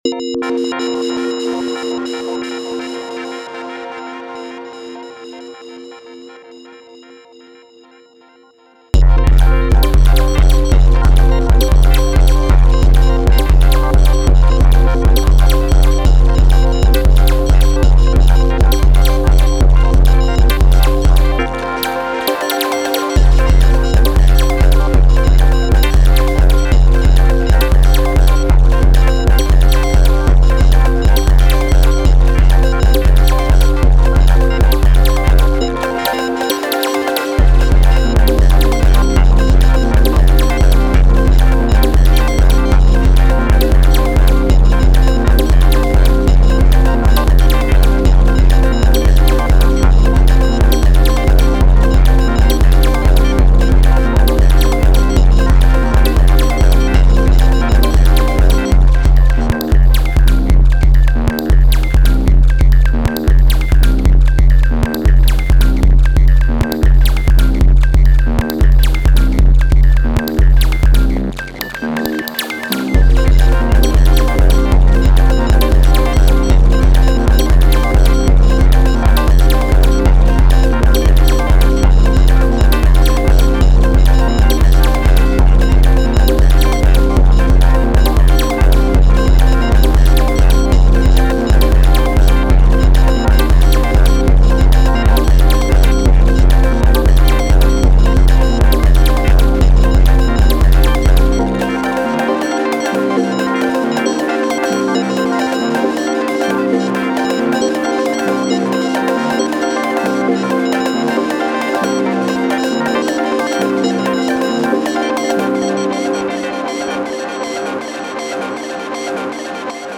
16step crunchy model:cycles pattern through BIM